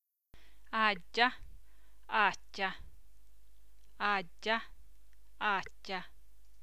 Bokstavkombinasjonen ‘ddj‘ står for en stemt dobbeltkonsonant, og ‘dj‘ står for en ustemt dobbelkonsonant.